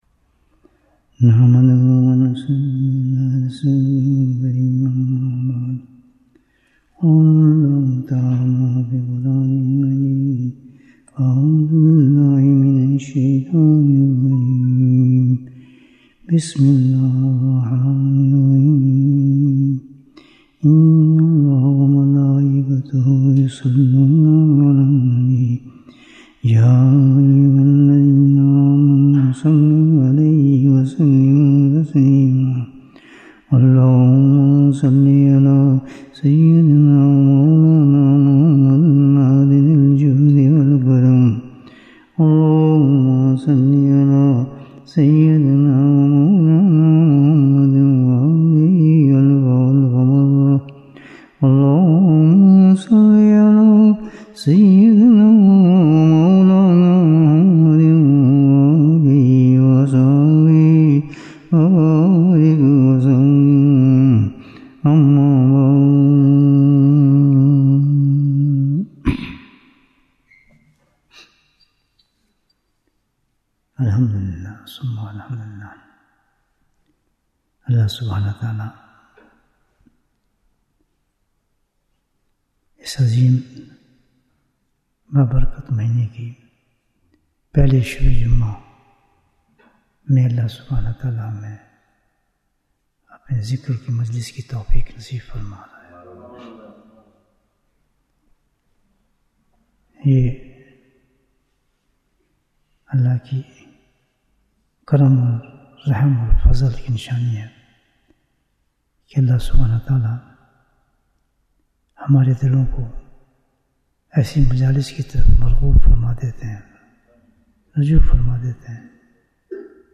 Bayan, 70 minutes28th August, 2025